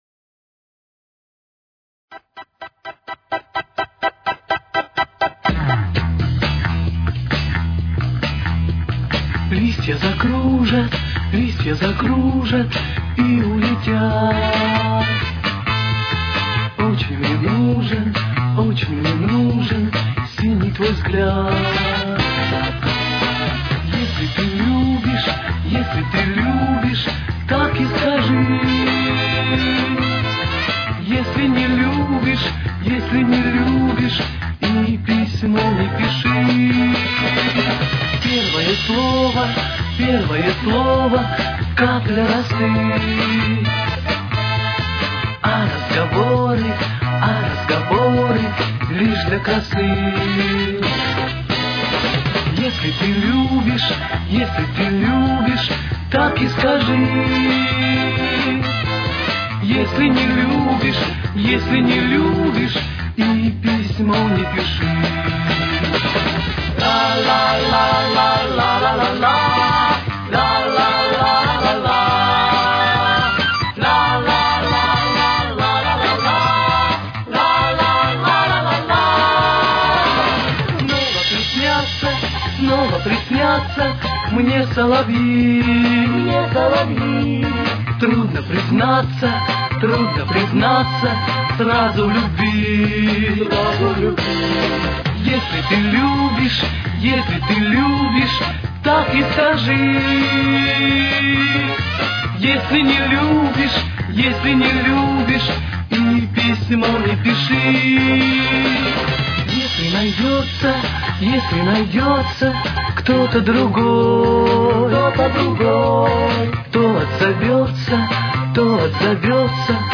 с очень низким качеством (16 – 32 кБит/с)
Ми минор. Темп: 137.